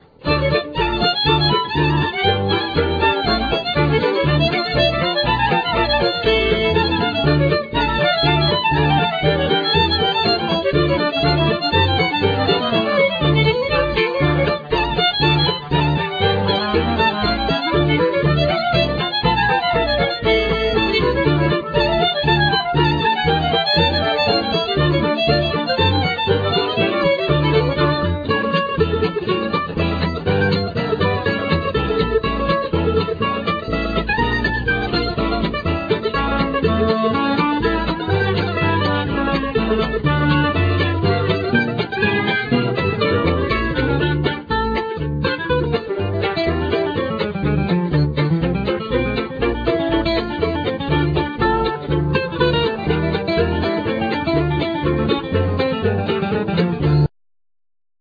Vocal
Violin
Mandolin,Koncovka
Double-bass
Gitar
Clarinet,Tarogato
Banjo,Low-whistle,Tarogato